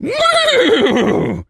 pgs/Assets/Audio/Animal_Impersonations/horse_neigh_02.wav at master
horse_neigh_02.wav